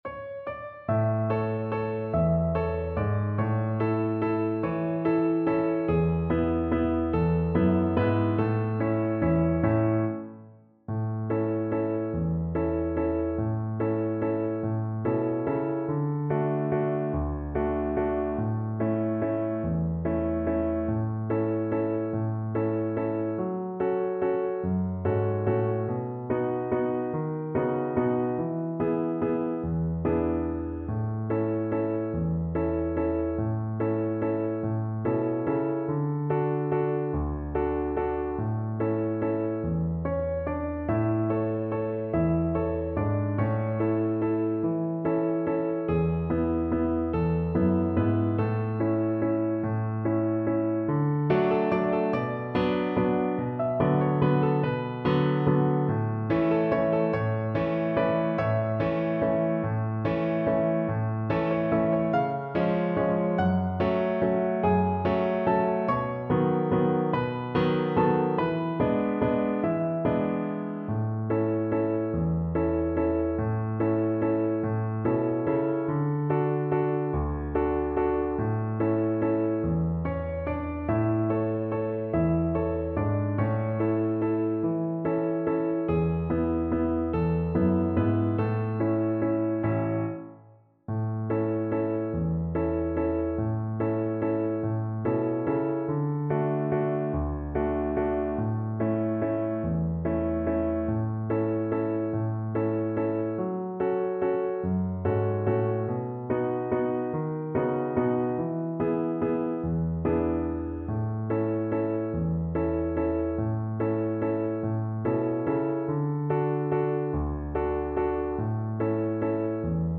Violin version
3/4 (View more 3/4 Music)
One in a bar .=c.48
Violin  (View more Easy Violin Music)